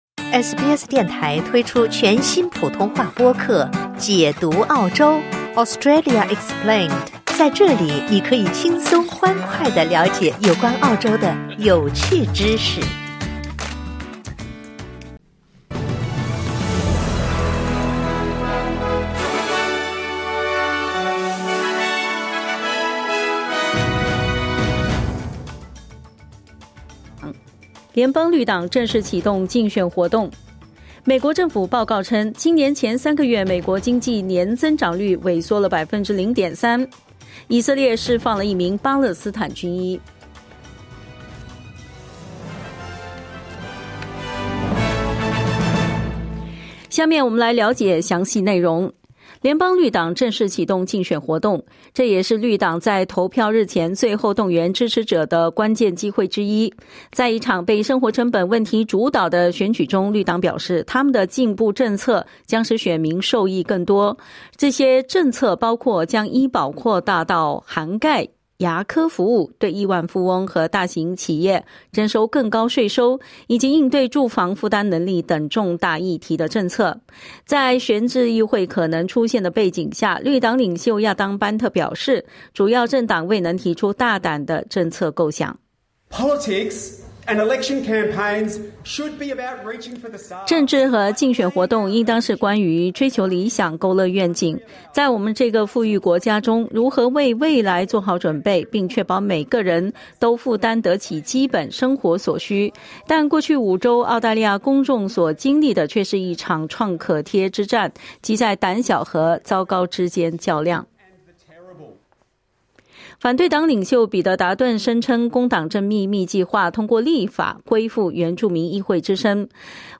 SBS早新闻 (2025年5月1日)